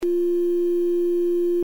AUDIBLE PITCH: 349.092 Hz (octave 0)